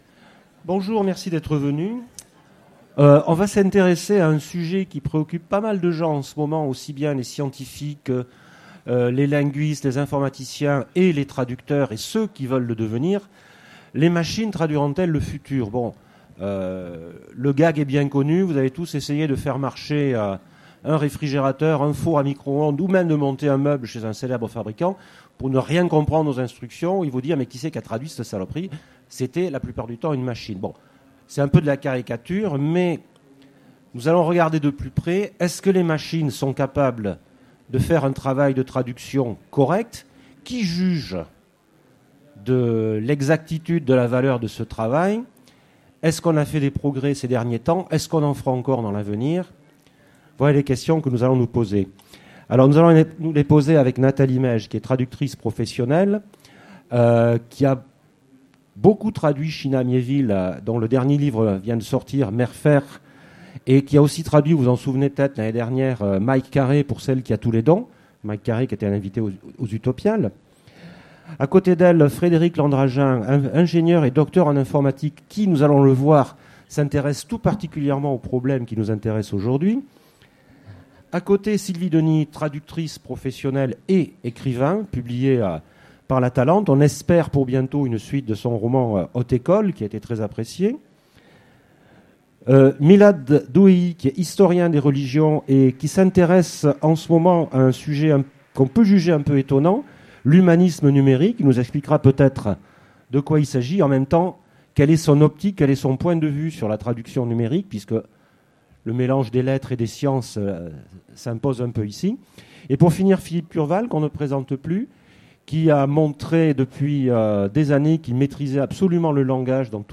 Utopiales 2016 : Conférence Les machines traduiront-elles le futur ?